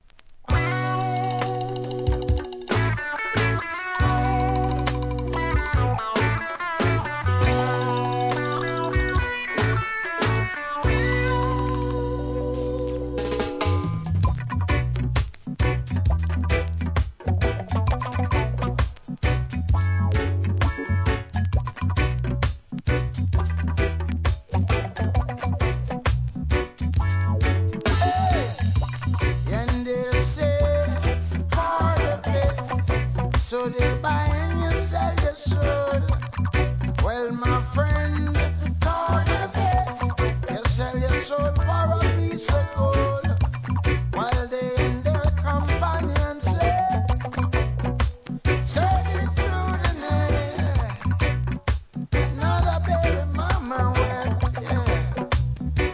REGGAE
コンシャス・チュ〜ン!!